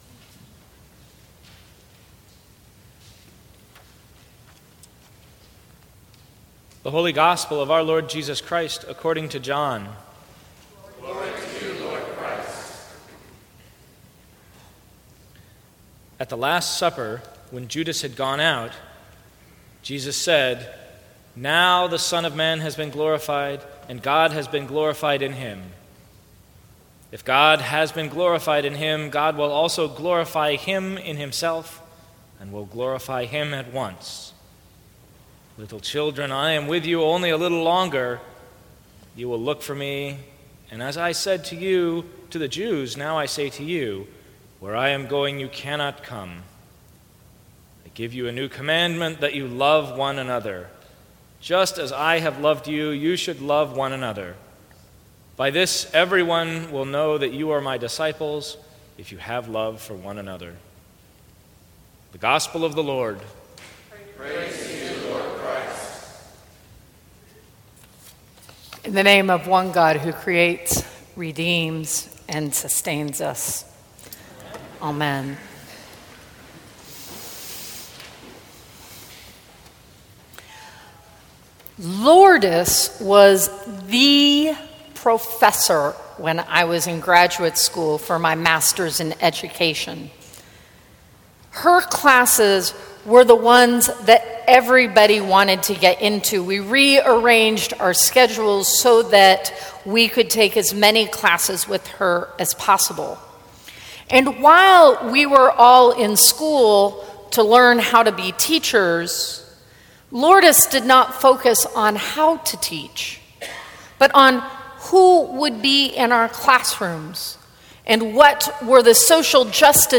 Sermons from St. Cross Episcopal Church The Fifth Sunday in Easter Apr 24 2016 | 00:16:22 1x 00:00 / 00:16:22 Subscribe Share Apple Podcasts Spotify Overcast RSS Feed Share Link Embed